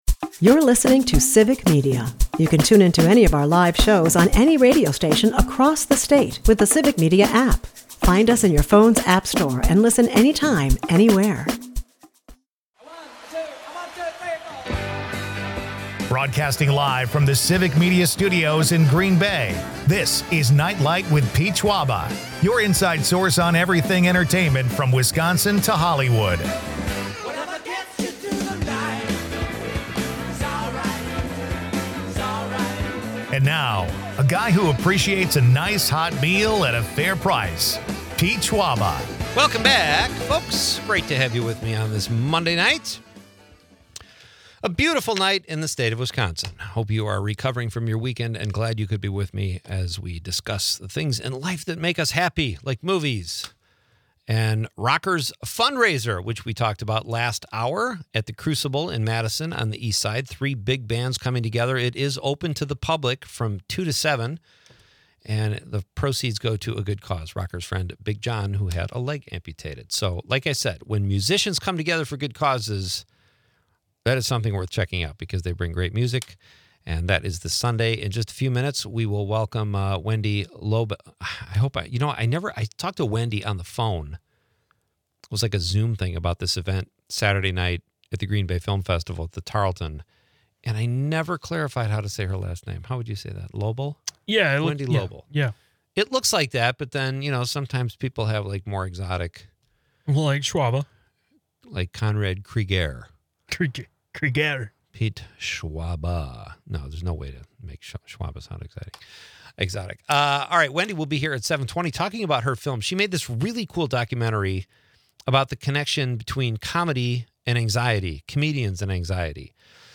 There's a spirited discussion on the best beers in Wisconsin, with listeners weighing in on their favorites like Spotted Cow and Blue Moon.
Amidst tech hiccups and local anecdotes, the show captures the quirky charm of Wisconsin's cultural scene.